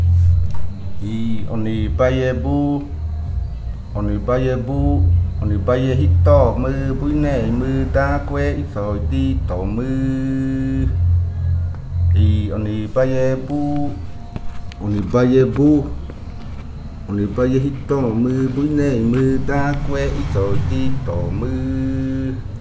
Leticia, Amazonas, (Colombia)
Grupo de danza Kaɨ Komuiya Uai
Canto fakariya de la variante Muinakɨ (cantos de la parte de abajo).
Fakariya chant of the Muinakɨ variant (Downriver chants).